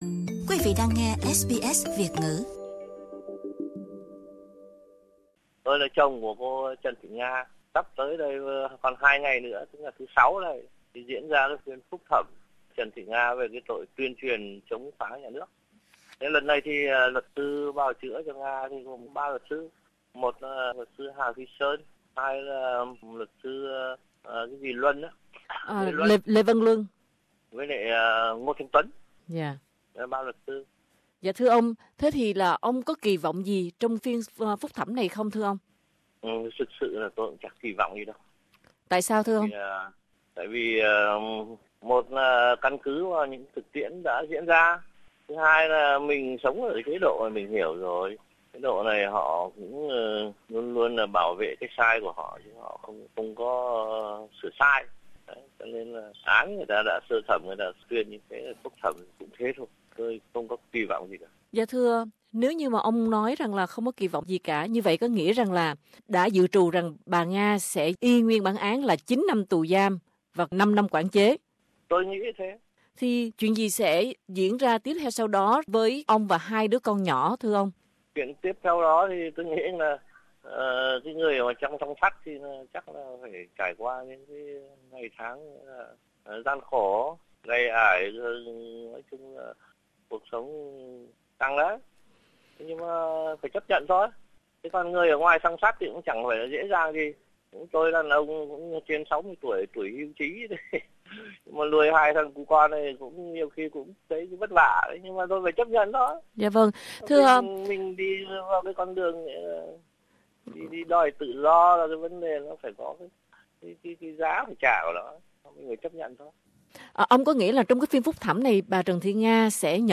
Trong cuộc trò chuyện